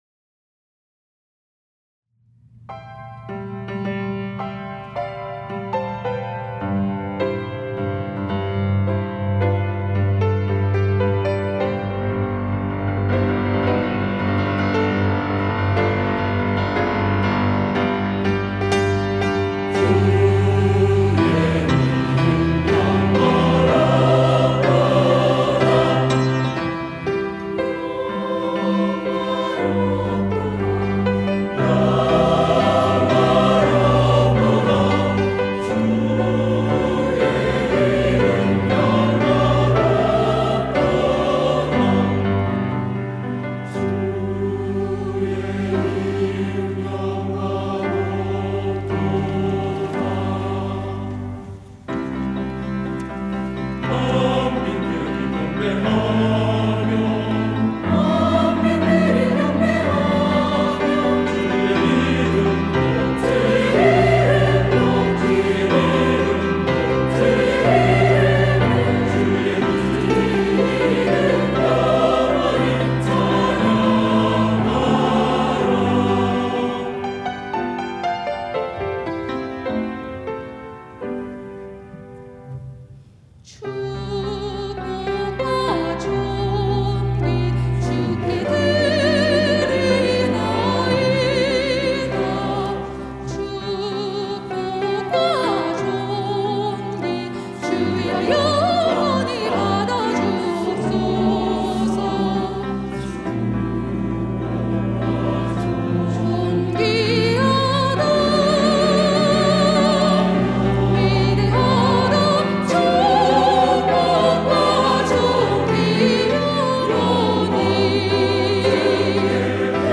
할렐루야(주일2부) - 주의 이름 영화롭도다
찬양대